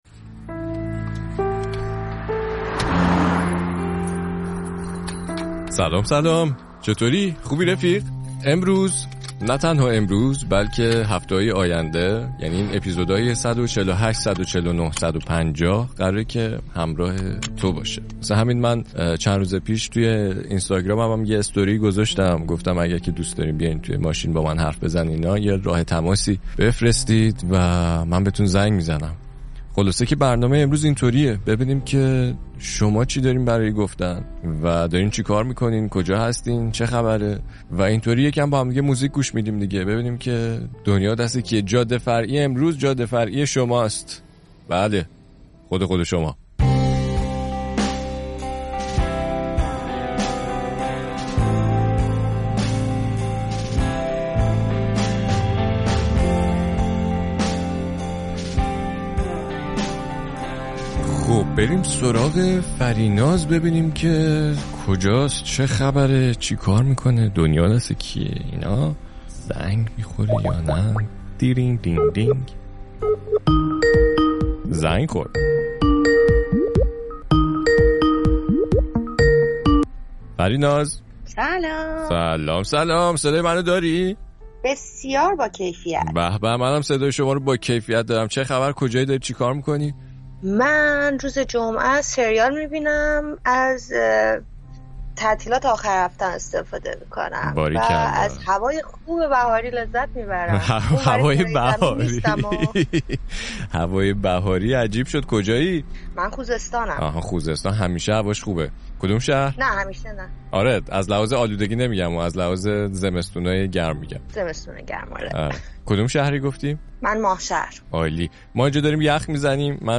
گپ می‌زنید و خاطره تعریف می‌کنید !